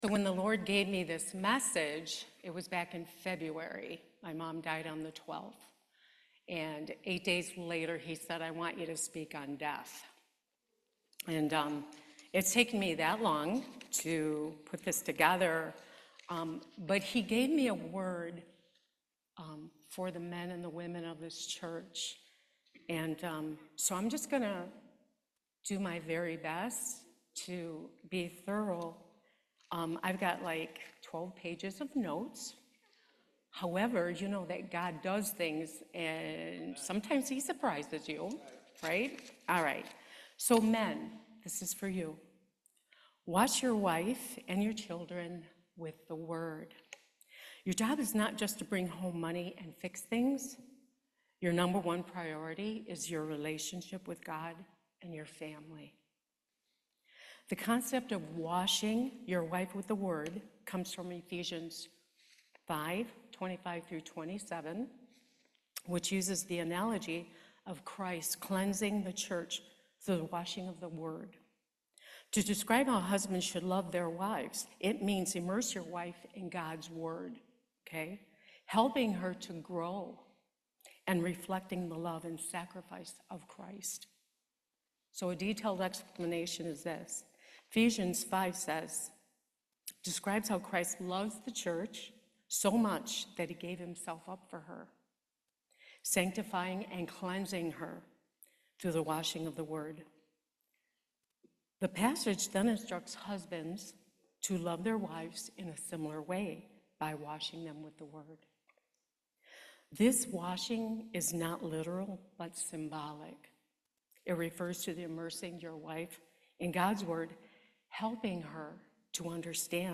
Service Type: Main Service